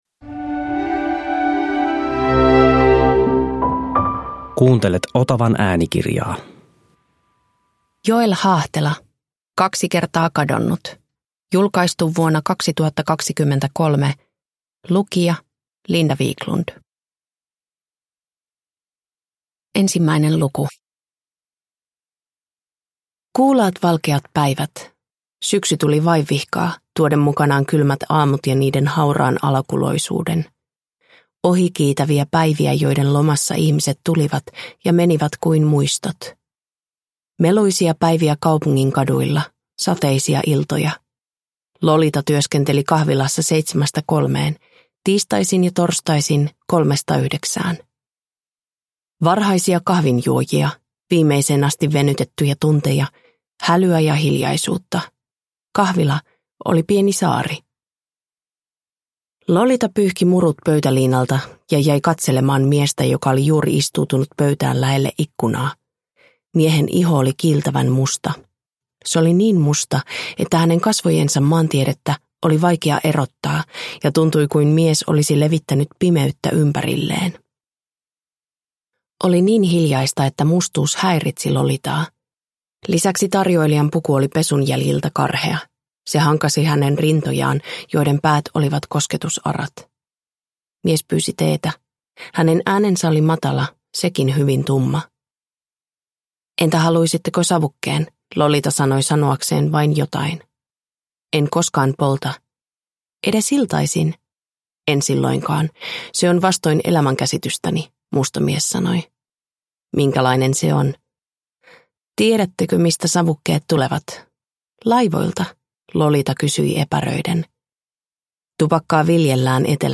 Kaksi kertaa kadonnut – Ljudbok – Laddas ner